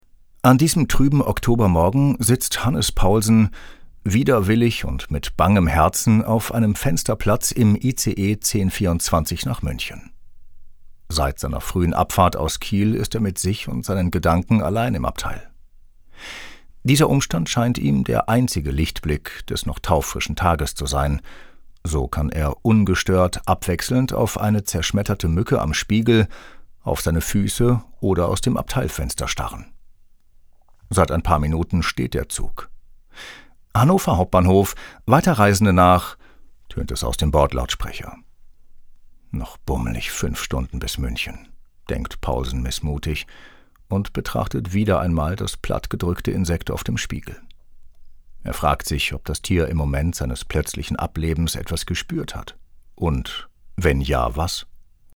markant, dunkel, sonor, souverän, sehr variabel, plakativ
Mittel plus (35-65)
Hörbuch "ICE 1024" (Jess Hansen)